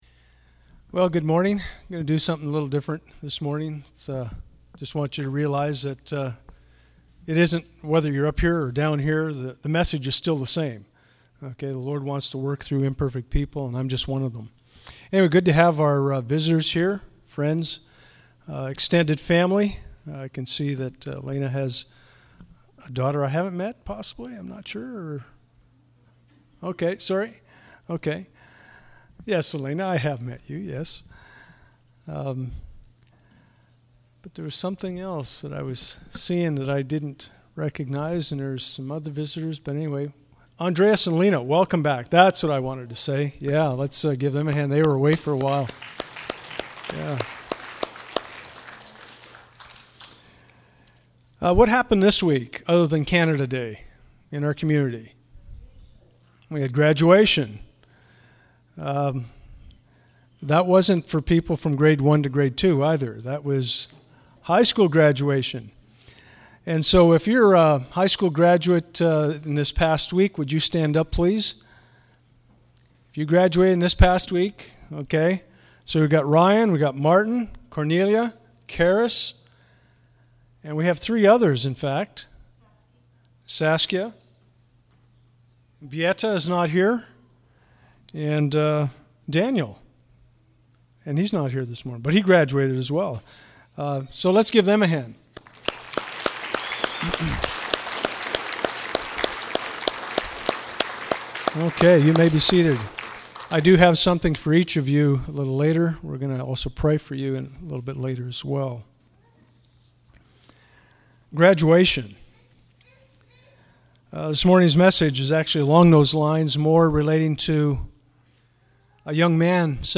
Passage: Genesis 37:1-10 Service Type: Sunday Morning « Child Dedication Service